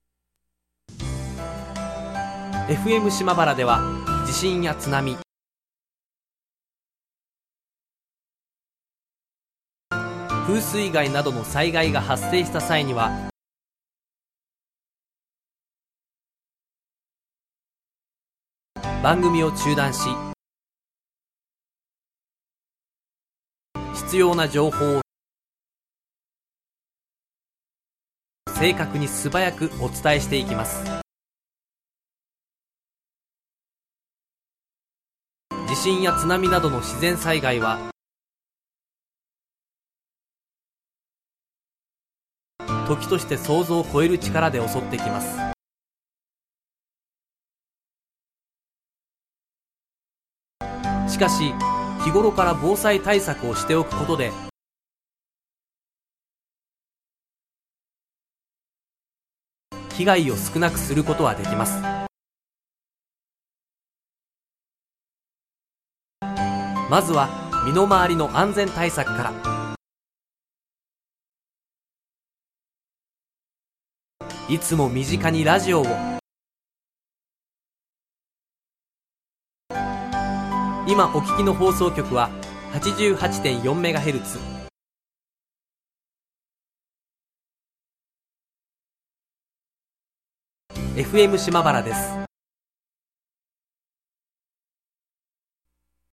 Natural Speed with Pauses